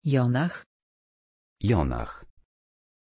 Pronunciation of Jonah
pronunciation-jonah-pl.mp3